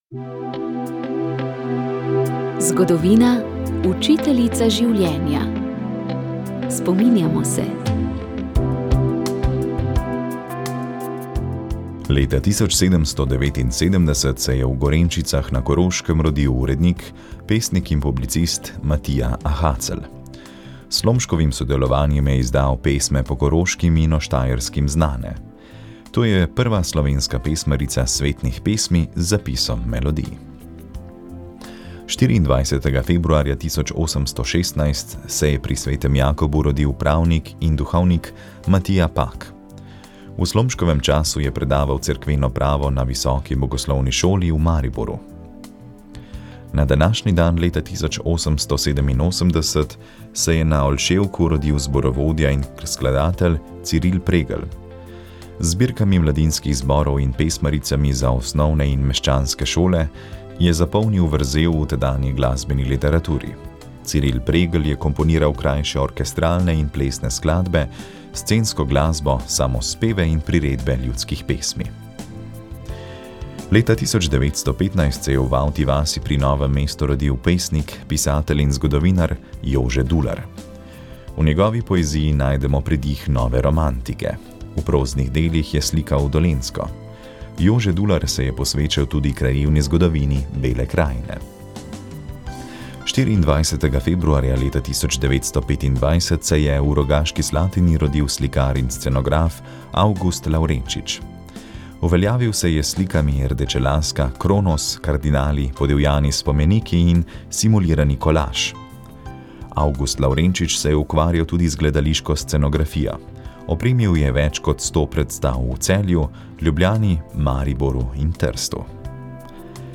V oddaji Od slike do besede smo tokrat pred začetkom Tedna Karitas objavili posnetek okrogle mize z naslovom Srce, ki sprejema, srce, ki podarja. Ta je minuli konec tedna potekala v vipavskem dvorcu Lanthieri, kjer so ob tej prilki odprli razstavo del, ki so avgusta nastala na likovni koloniji Umetniki za Karitas. Tema je bila umetnost in dobrodelnost.